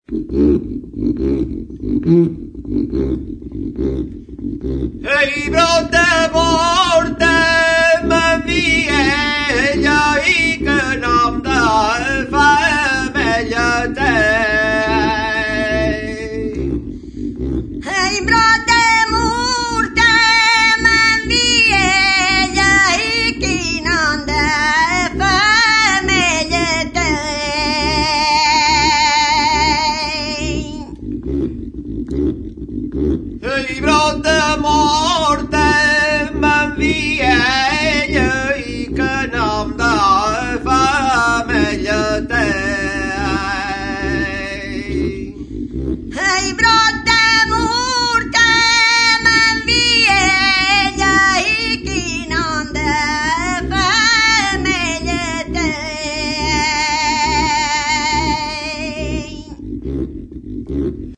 SIMBOMBA; XIMBOMBA | Soinuenea Herri Musikaren Txokoa
Instruments de musique: SIMBOMBA; XIMBOMBA Classification: Membranophones -> Frictionnés -> Tige Emplacement: Erakusketa; menbranofonoak Explication de l'acquisition: Emana; Egileak emandakoa.